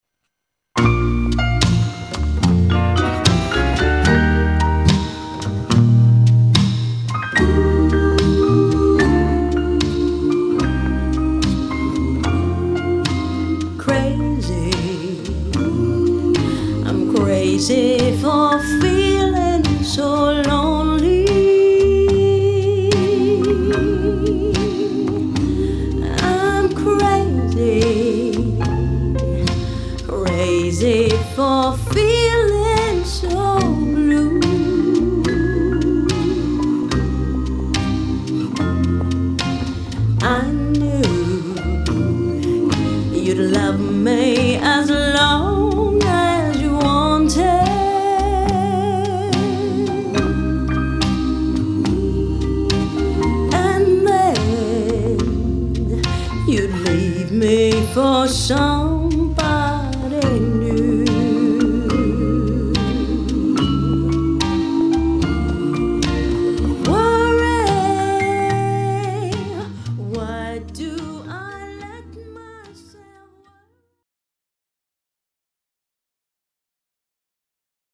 Wedding Singer